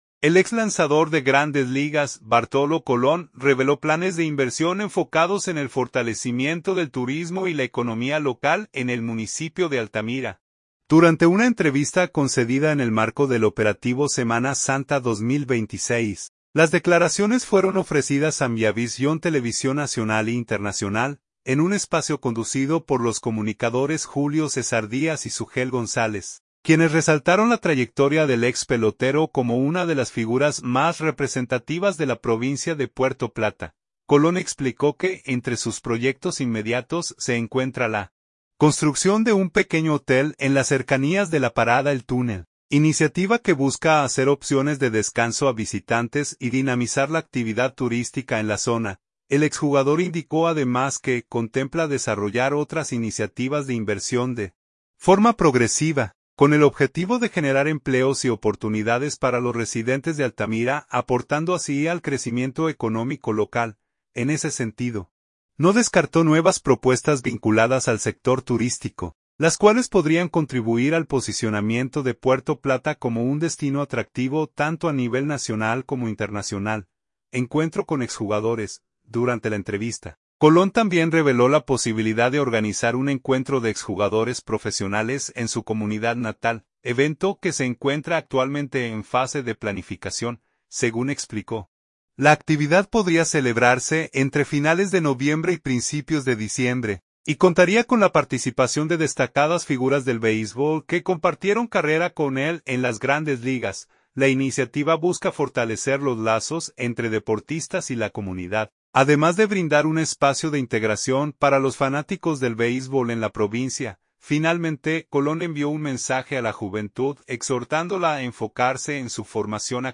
Altamira, Puerto Plata. — El ex lanzador de Grandes Ligas, Bartolo Colón, reveló planes de inversión enfocados en el fortalecimiento del turismo y la economía local en el municipio de Altamira, durante una entrevista concedida en el marco del Operativo Semana Santa 2026.